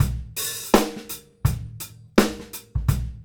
GROOVE 140DR.wav